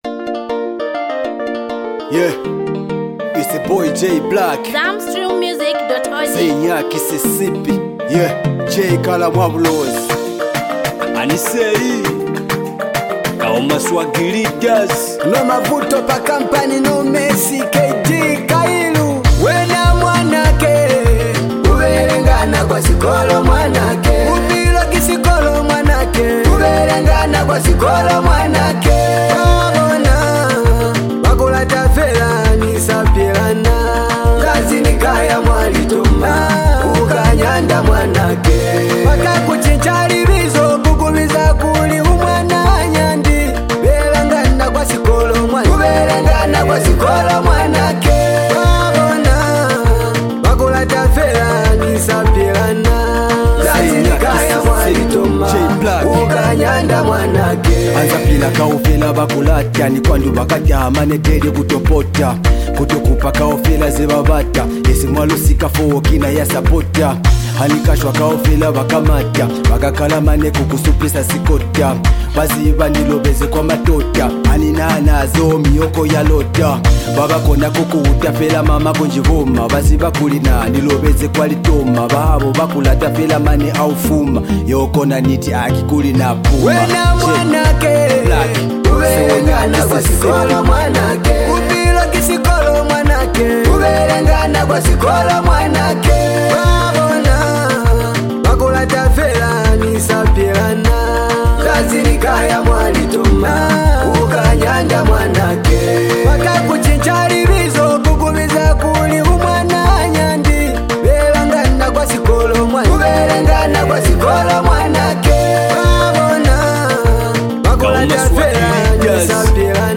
a compelling blend of emotion, rhythm, and lyrical finesse
smooth vocals and expressive delivery
With lyrical depth and melodic richness